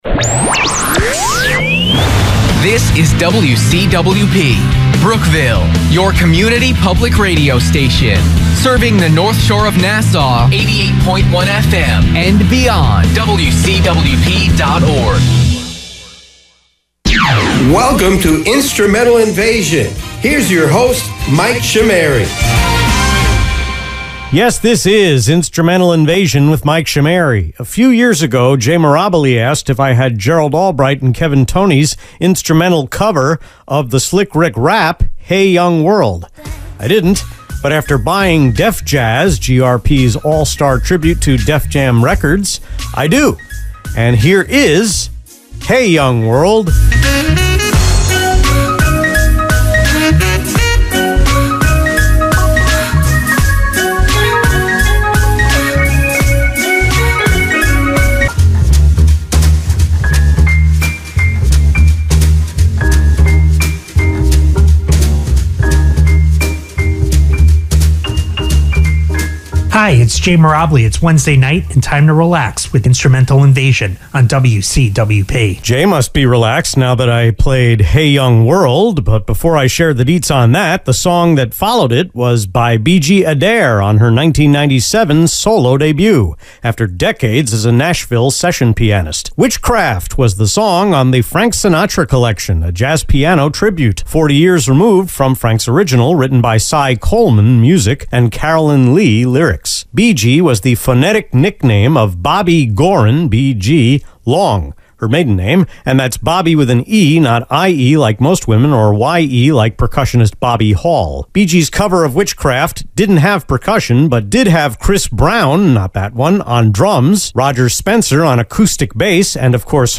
The September 28 Instrumental Invasion on WCWP was recorded in sequence (for a change) over three days in August: three segments (hour 1) on the 6th, one on the 7th, and two on the 8th.
The first and last songs went from starting as beds to starting cold, and I still had to add a liner going into the last talk break.